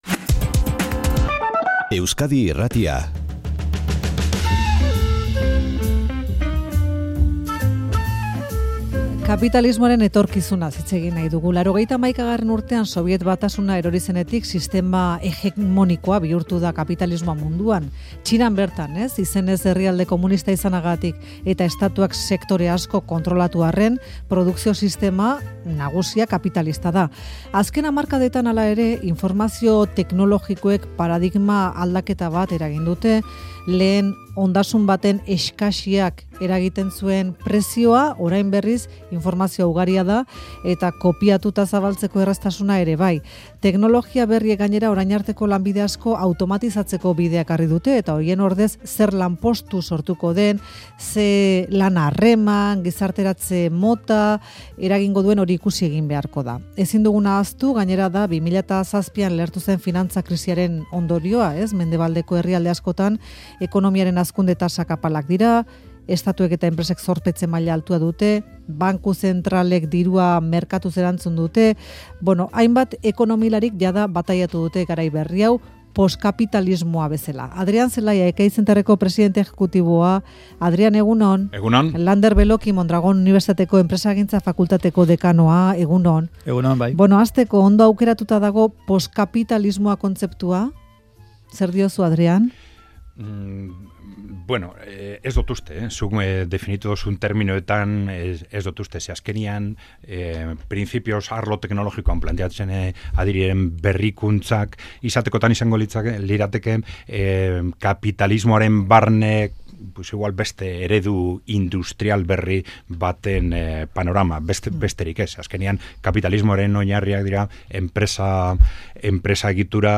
Euskadi Irratiko Faktorian, postkapialismoari buruzko eztabaida.